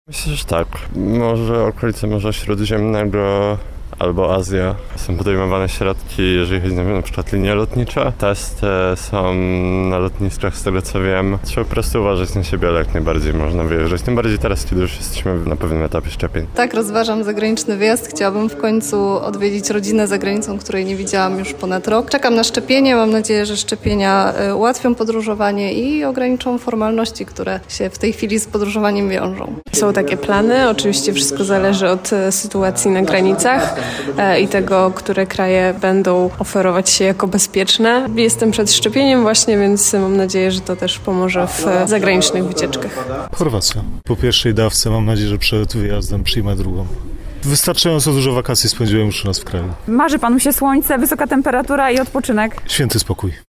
Aura-TD-wyjazdy-wakacyjne-sonda.mp3